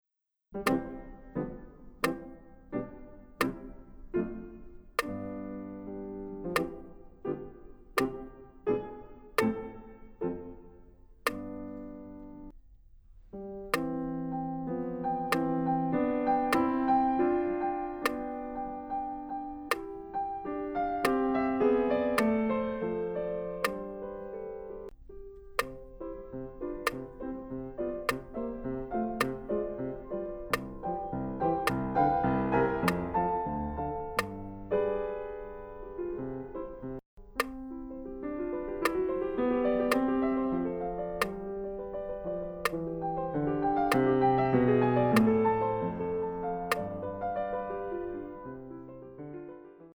Here you can hear the variations side to side, and I added a metronome to every second beat, count the notes in between the beats if you like: